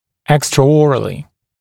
[ˌekstrə’ɔːrəlɪ][ˌэкстрэ’о:рэли]внеротовым методом; внешне (об оценке при осмотре); наружным доступом (хир.)